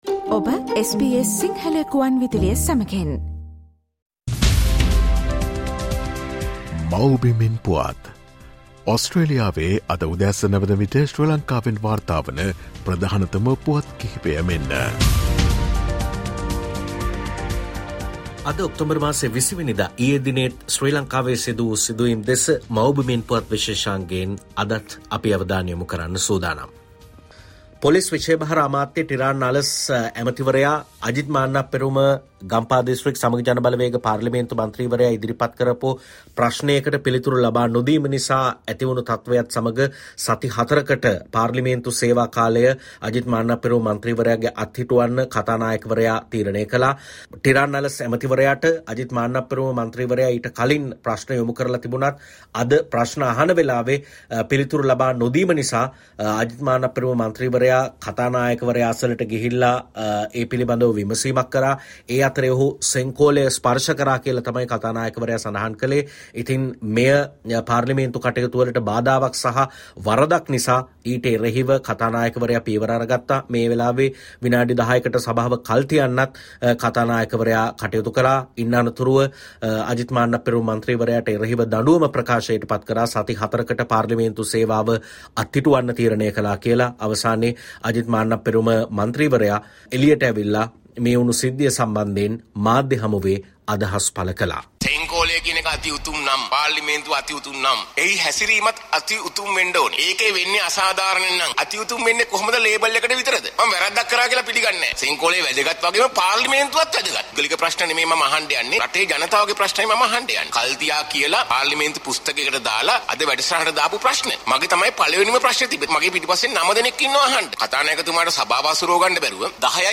SBS Sinhala featuring the latest news reported from Sri Lanka - Mawbimen Puwath